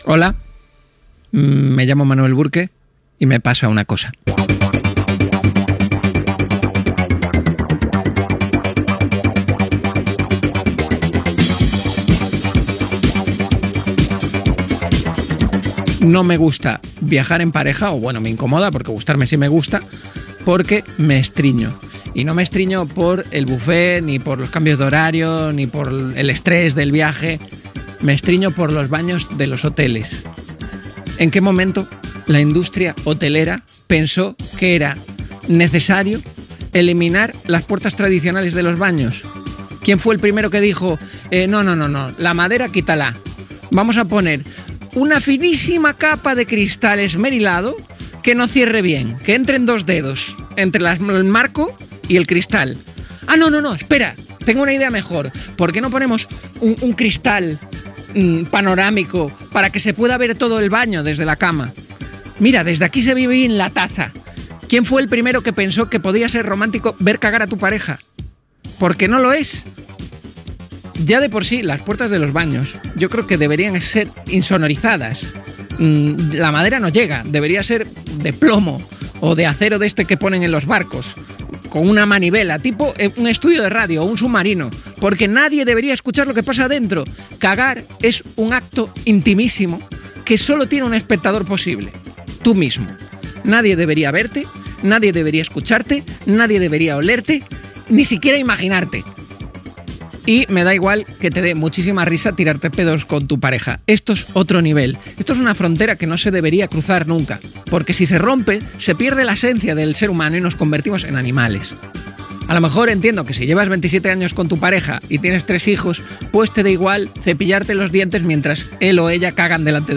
Monòleg irònic sobre els banys dels hotels